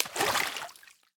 Minecraft Version Minecraft Version latest Latest Release | Latest Snapshot latest / assets / minecraft / sounds / block / cauldron / dye1.ogg Compare With Compare With Latest Release | Latest Snapshot